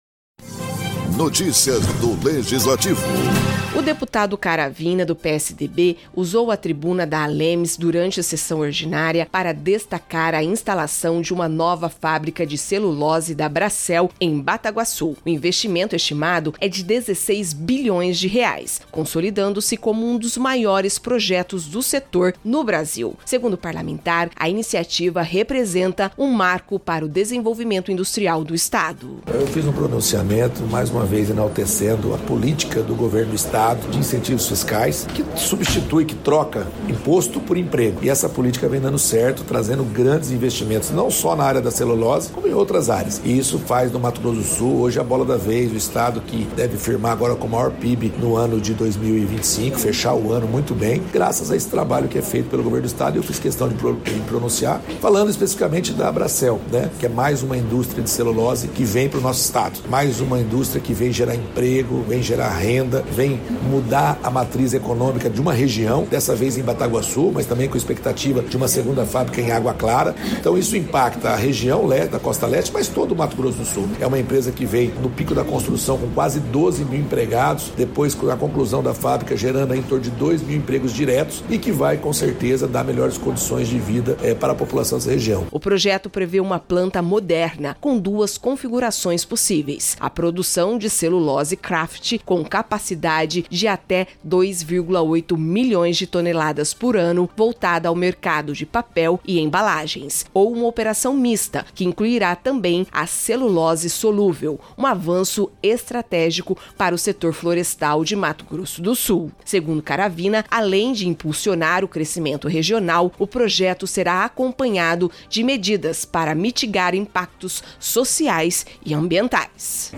O deputado Gerson Claro Caravina (PSDB) usou a tribuna da ALEMS para destacar a instalação da nova unidade industrial da Bracell em Bataguassu. Com aporte previsto de R$ 16 bilhões, o projeto é um dos maiores do setor em andamento no Brasil e promete transformar o perfil industrial do Estado.